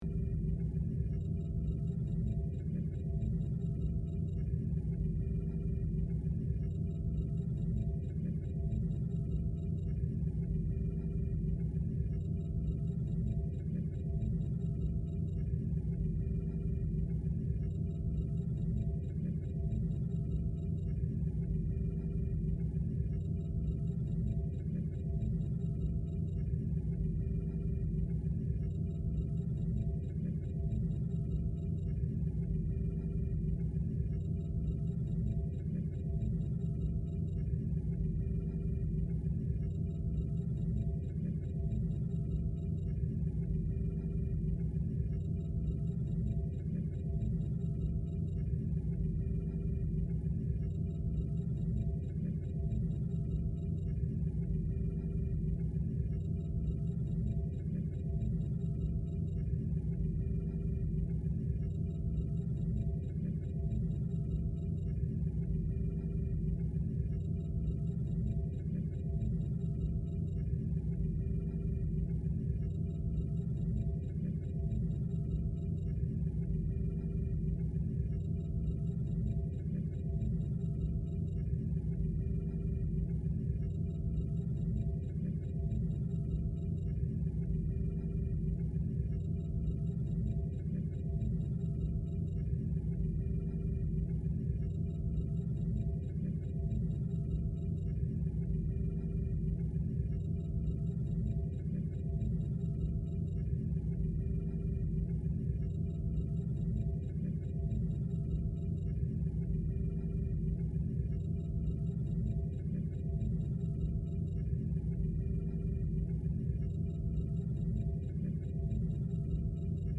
briefingroom.ogg